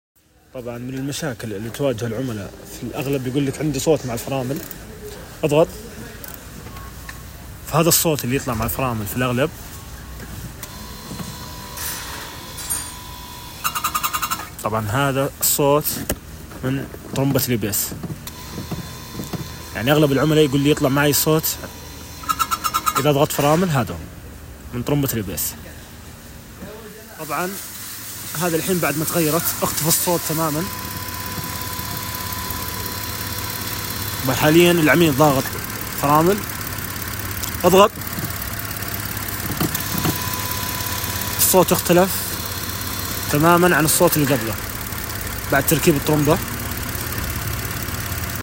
صوت مضخة الـABS وبعض المرات sound effects free download